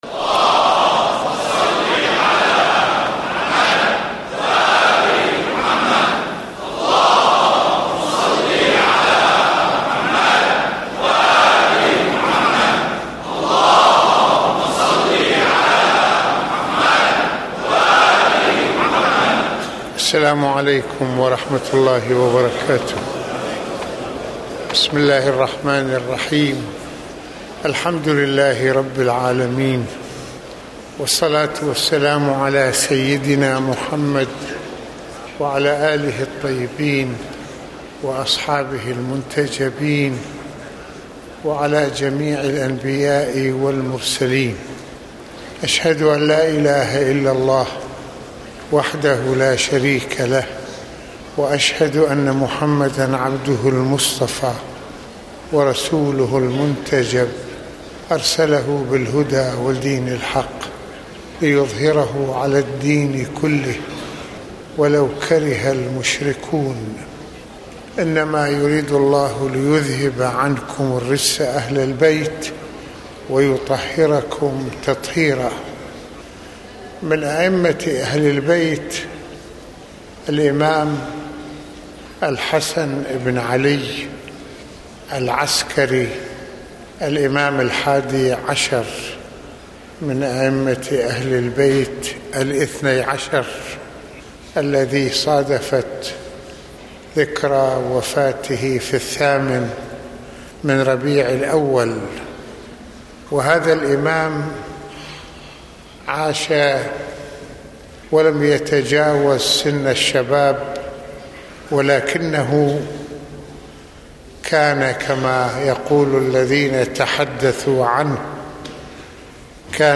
ملفات وروابط - المناسبة : خطبة الجمعة المكان : مسجد الإمامين الحسنين(ع) المدة : 19د | 28ث المواضيع : الإمام العسكري(ع): محامد الخصال في العلم والزهد وكمال العقل - ثقة الناس بأهل البيت(ع) - رصد الواقع الثقافي.